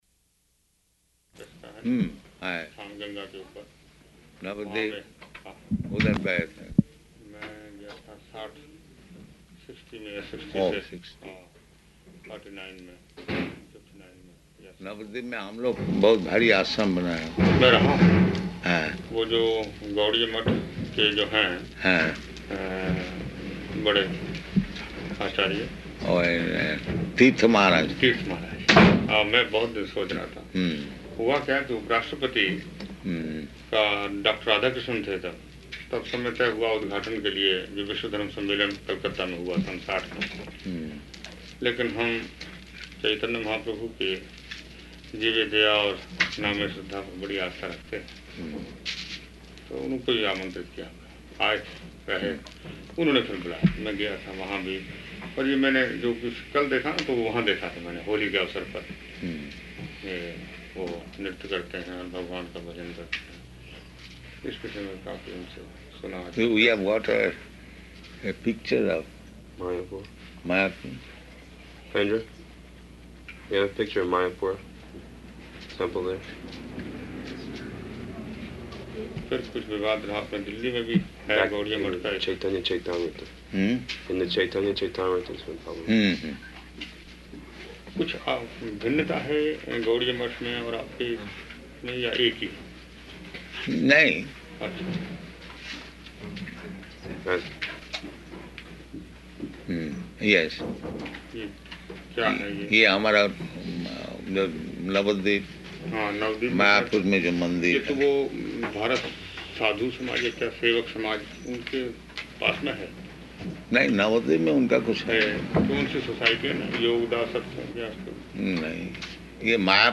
Type: Conversation
Location: San Francisco
[predominantly Hindi throughout]